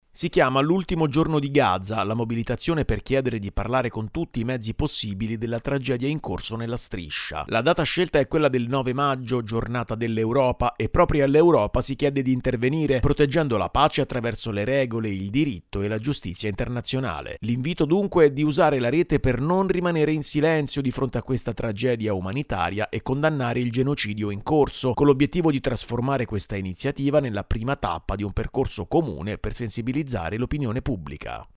Crescono le adesioni per la mobilitazione che vuole rompere il silenzio sulla strage che si consuma nella Striscia. Il servizio